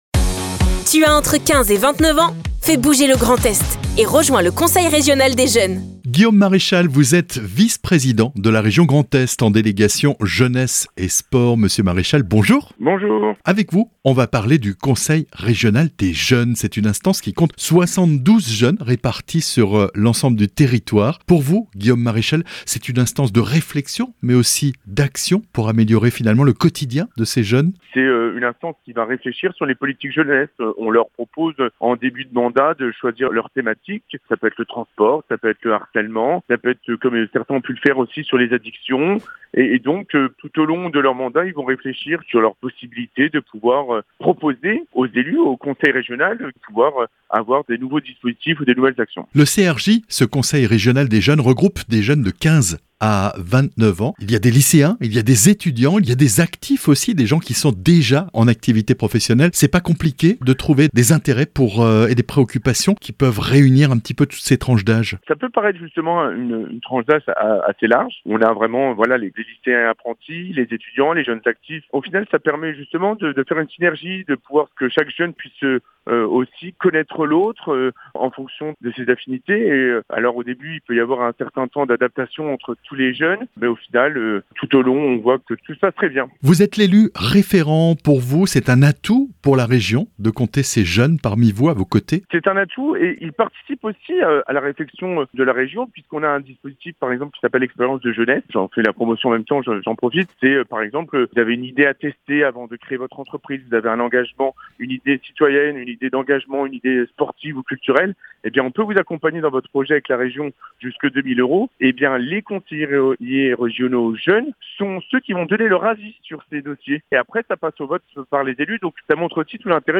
Guillaume MARECHAL - Vice-Président de la Région Grand Est, en délégation Jeunesse et Sport.